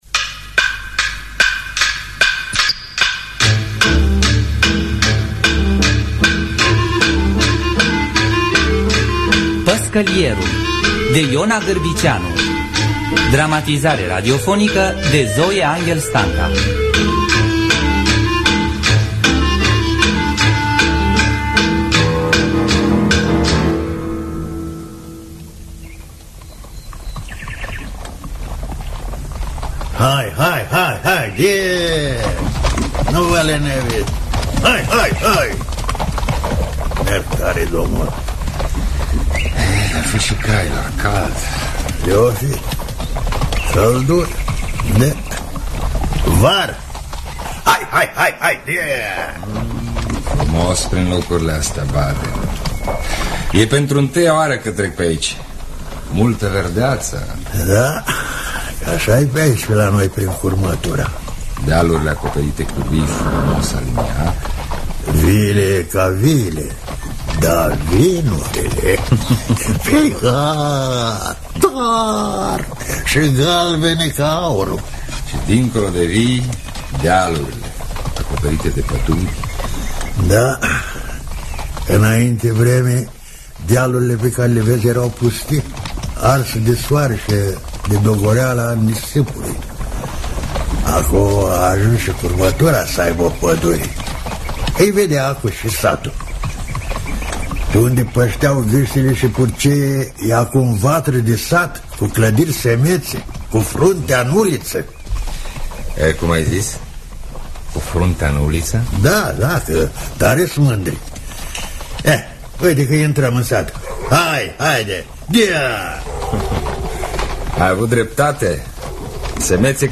Păşcălierul de Ion Agârbiceanu – Teatru Radiofonic Online